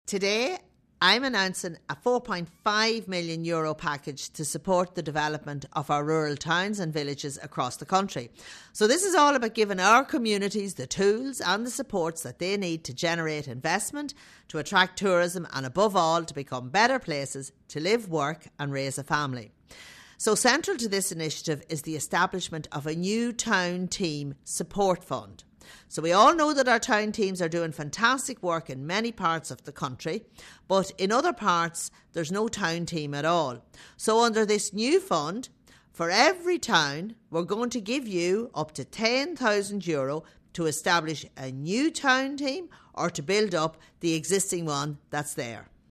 Minister Humphreys says funding will be made available to set up a designated town team: